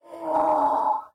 mob / horse / donkey / angry1.ogg